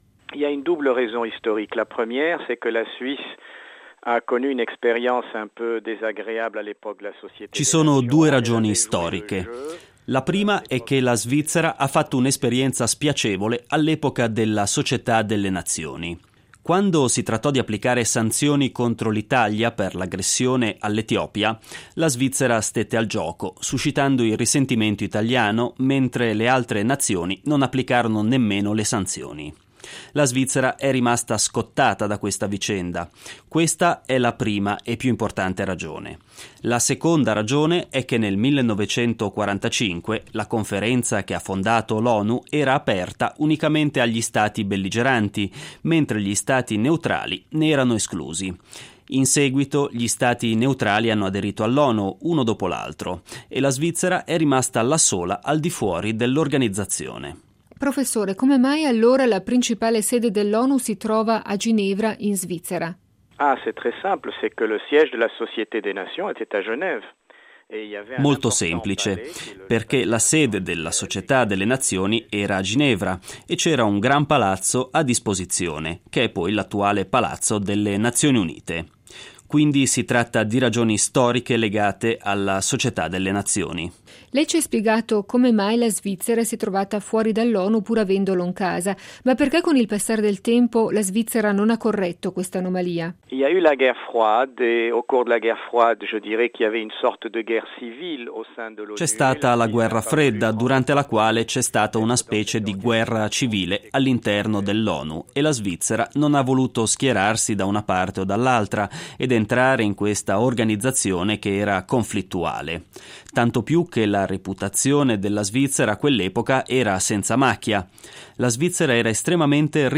Intervista
Dagli archivi di Radio Svizzera Internazionale (23 settembre 1997).